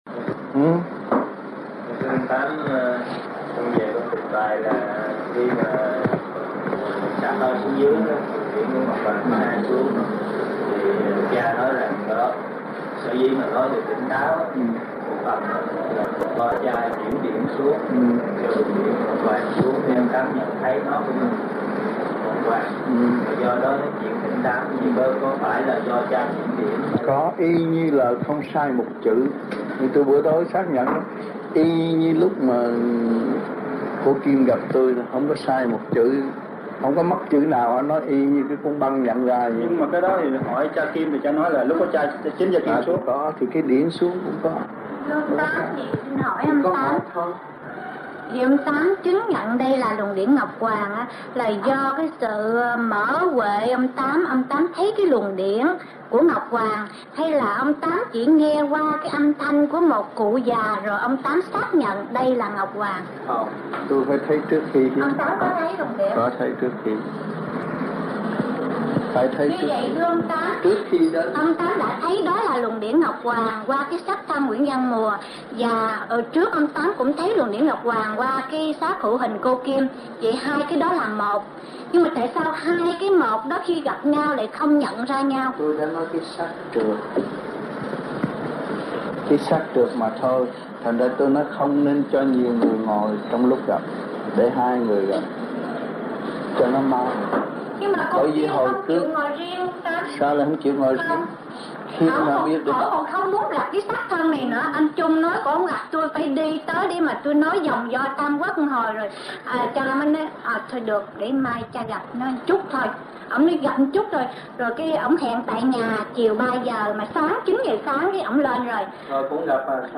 1978 Đàm Đạo